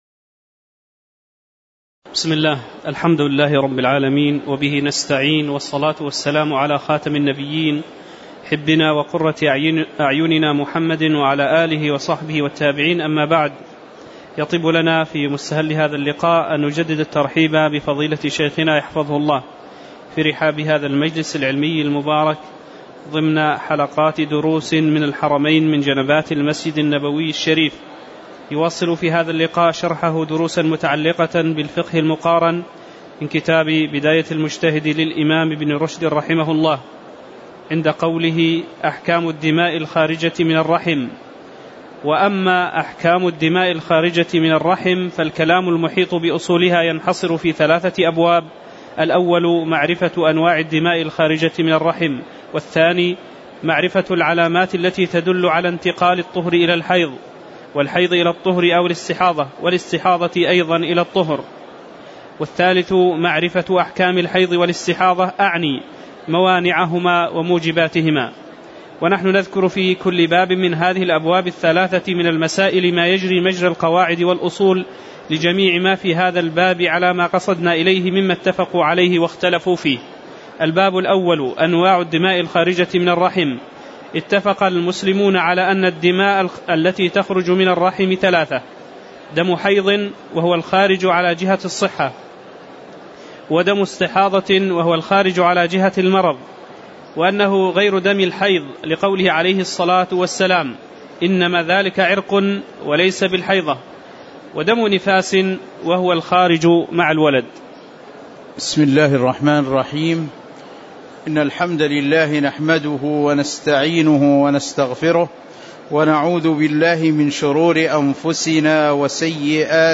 تاريخ النشر ٧ جمادى الأولى ١٤٤٠ هـ المكان: المسجد النبوي الشيخ